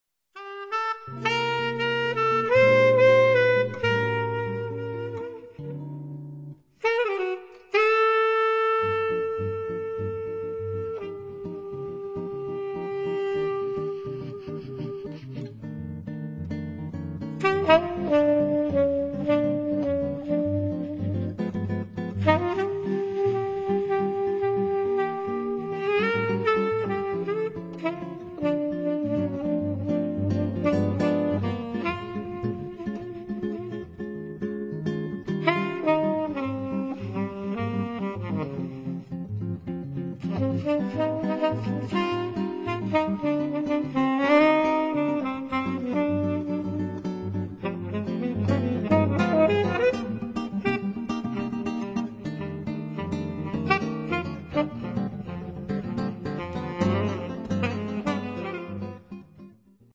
Saxophone
Guitar